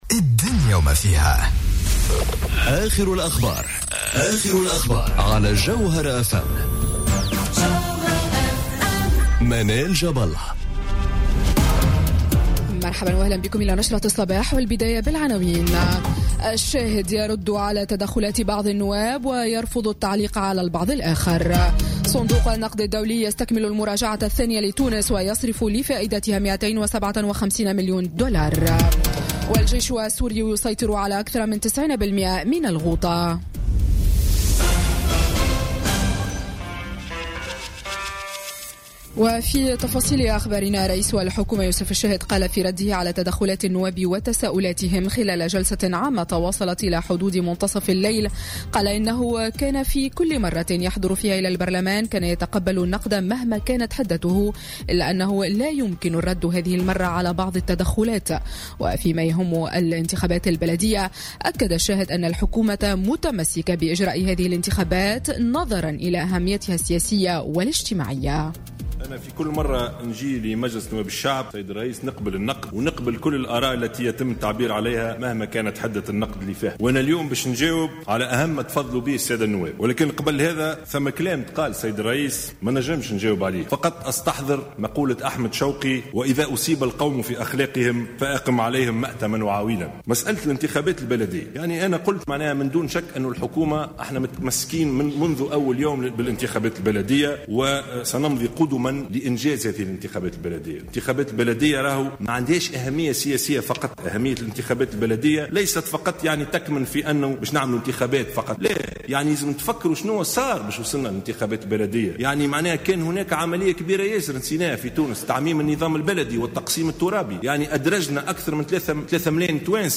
نشرة أخبار السابعة صباحا ليوم السبت 24 مارس 2018